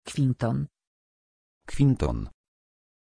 Pronunciation of Quinton
pronunciation-quinton-pl.mp3